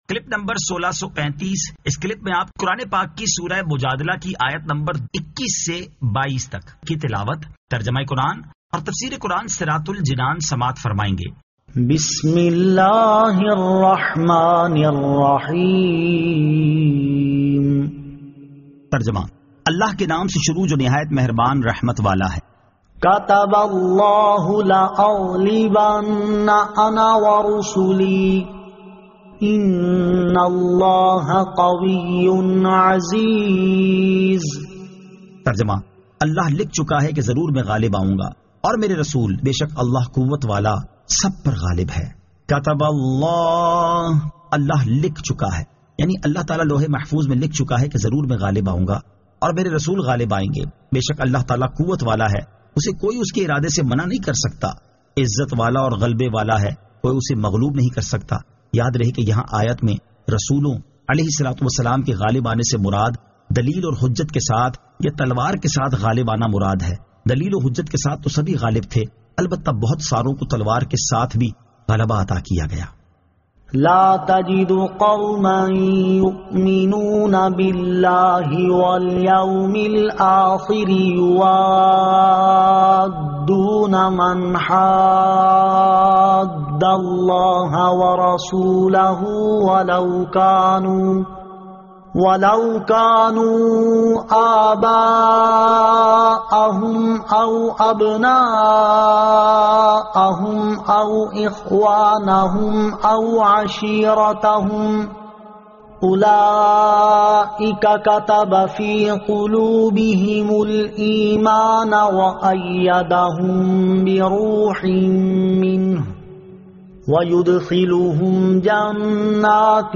Surah Al-Mujadila 21 To 22 Tilawat , Tarjama , Tafseer
2024 MP3 MP4 MP4 Share سُوَّرۃُ المُجَادِلَۃ آیت 21 تا 22 تلاوت ، ترجمہ ، تفسیر ۔